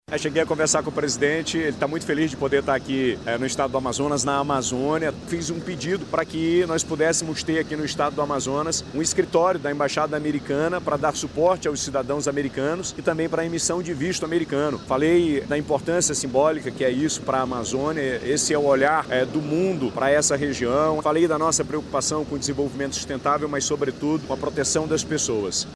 O desembarque aconteceu na tarde desse domingo (17), no Aeroporto Internacional Eduardo Gomes.
Na ocasião, o chefe do executivo estadual Wilson Lima, agradeceu a visita e falou sobre a preservação da floresta e da população amazônica.